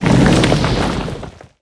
earth_atk_01.ogg